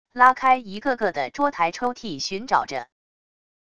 拉开一个个的桌台抽屉寻找着wav音频